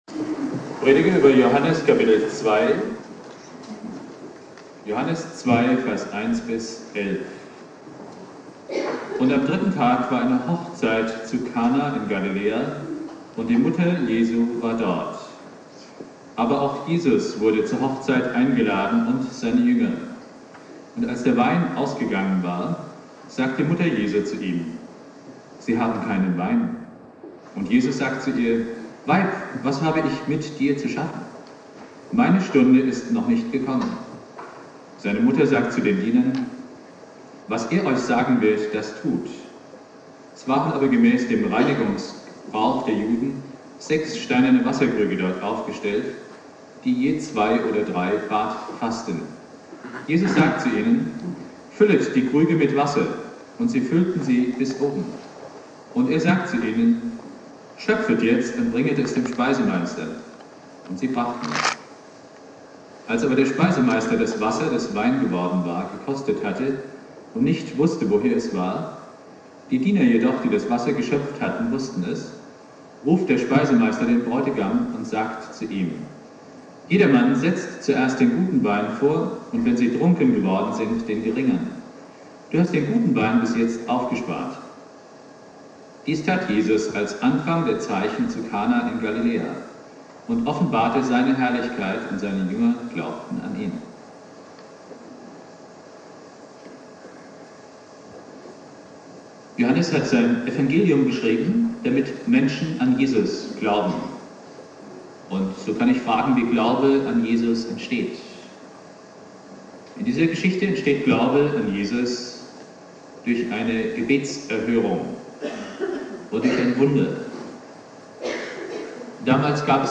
"Hochzeit zu Kana" (mit Außenmikrofon aufgenommen) Bibeltext